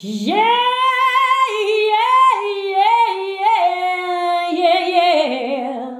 YEAHIYEAH.wav